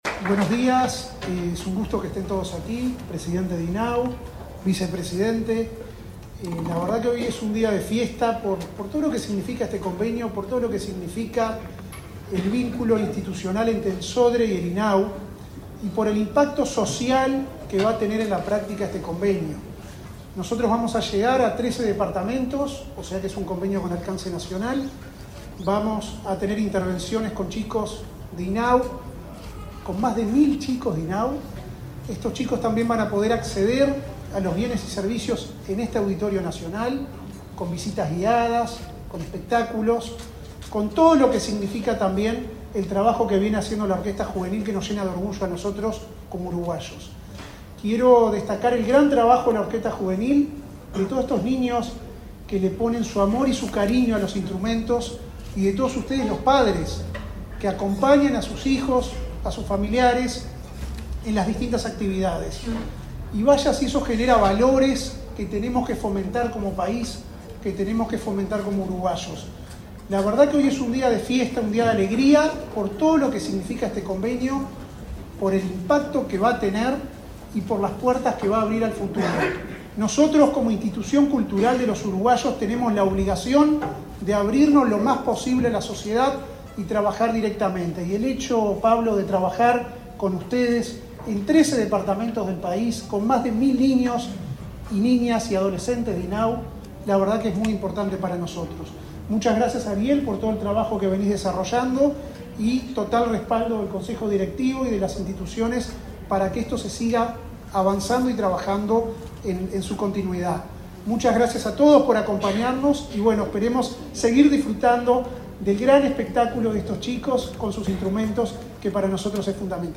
Palabras de autoridades en convenio entre INAU y Sodre